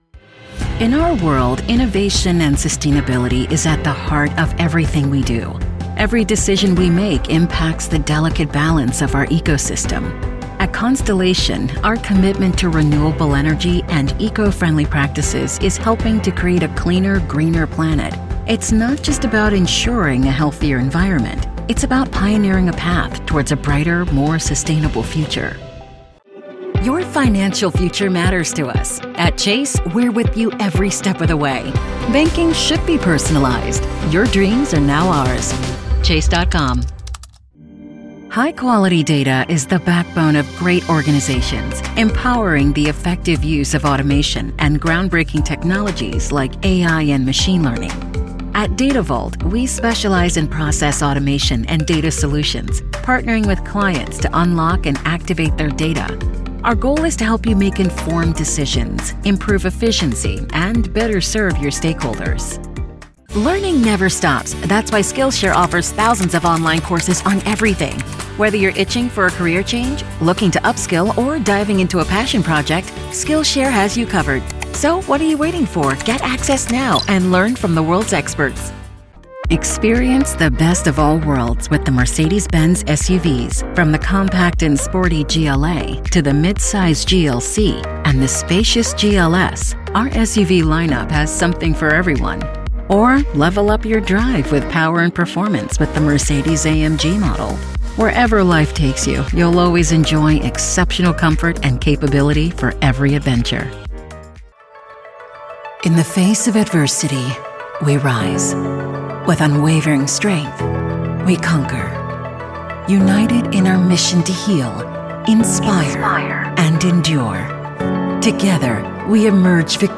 Distinctive, Mature, Amicale, Chaude, Corporative
Vidéo explicative